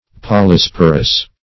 Polysporous \Pol`y*spor"ous\, a.